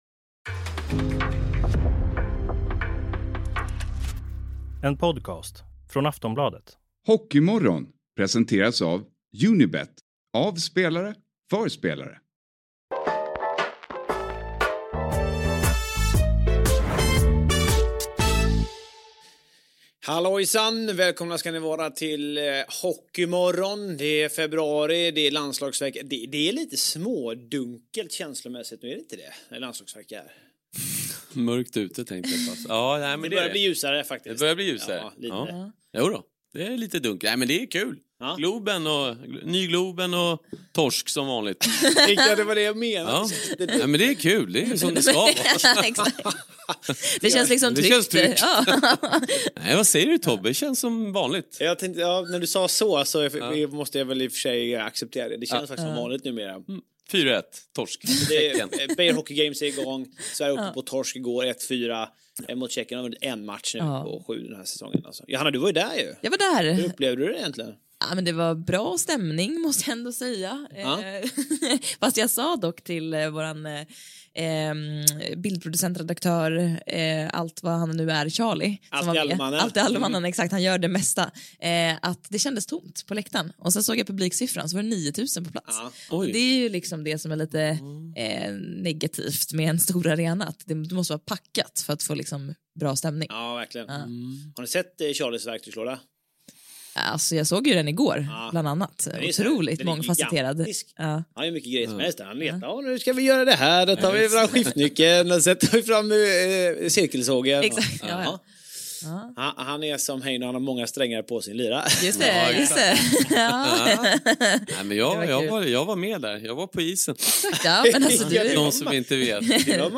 Vi lyssnar också till spelarkommentarer efter förlusten mot Tjeckien innan vi går på det som komma skalla "over there" om en vecka. Det är dags för Four Nations och vi synar Tre Kronors trupp, tar ut våra femmor och spekulerar i Sveriges chanser.
Gäst på länk: Filip Forsberg